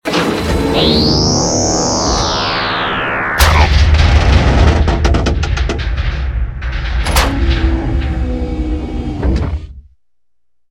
clamps.wav